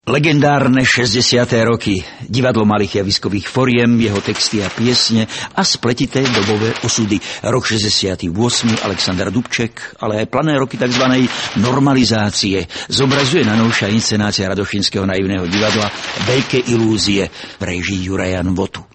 Radošinské naivné divadlo Bratislava
Inscenace uvedena na scéně VČD v rámci nesoutěžního programu GRAND Festivalu smíchu v únoru 2008.
Hudobníci a speváci:
hráč na klavíri, gitare a bendže, spev
hráč na kontrabase a bendže
hráč na klavíri, akordeóne a trúbke, spev
hráčka na saxofóne a akordeóne, spev
hráč na bicie nástroje